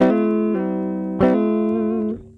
Gtr_01.wav